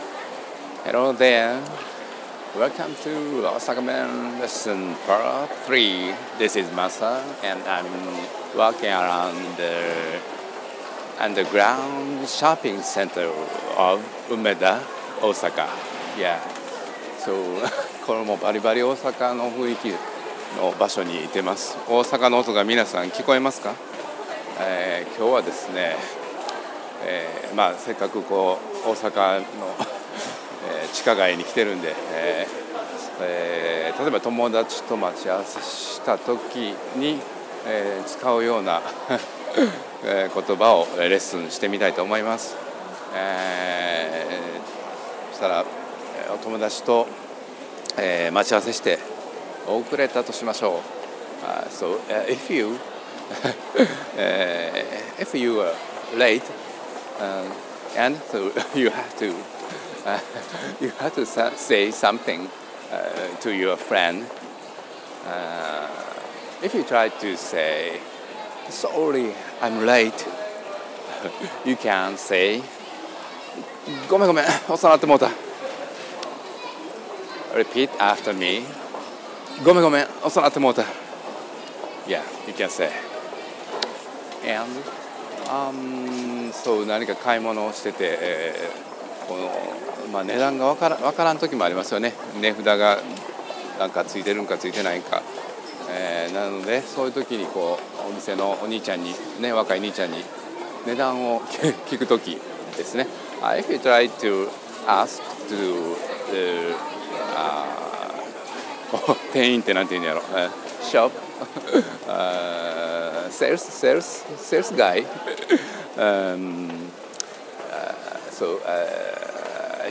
Let's Try An Osakan Accent